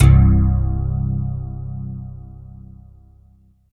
47 BASS 80-L.wav